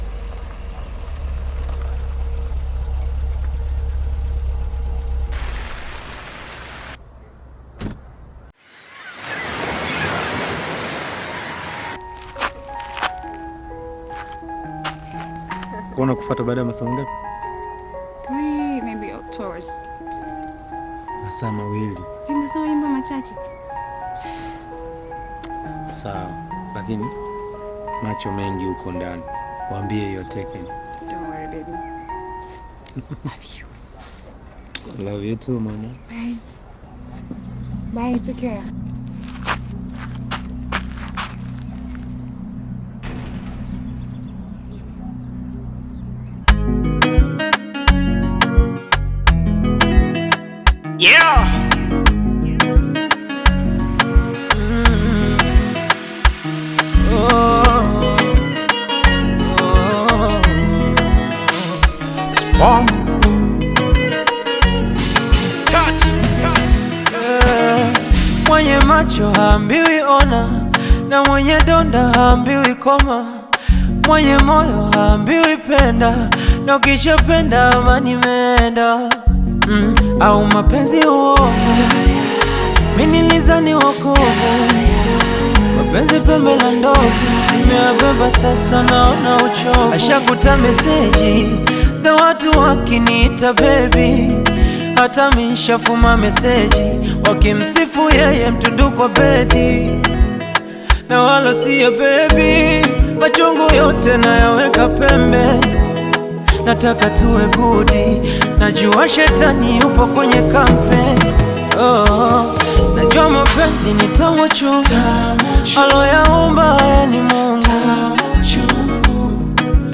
emotive Afro-Pop/Bongo Flava single